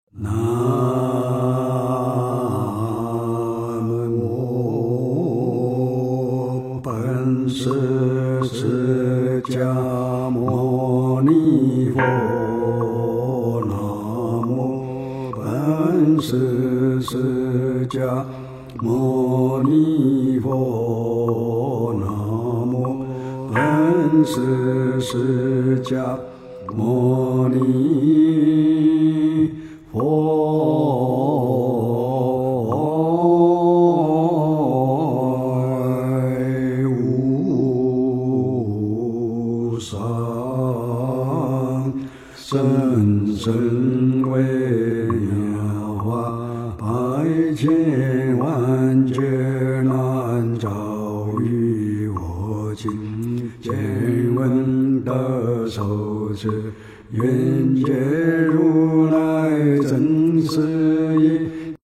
诵经 南无本师释迦牟尼佛(三称
佛音 诵经 佛教音乐 返回列表 上一篇： 六字大明咒 下一篇： 三皈依 相关文章 峡谷回声Echo in the Hills--未知 峡谷回声Echo in the Hills--未知...